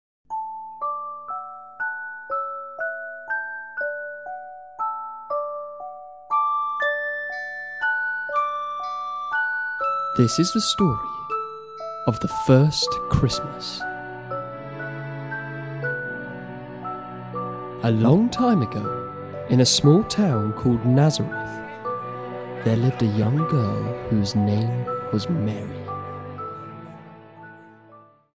The Nativity story read by our storyteller, with 8 of our most popular songs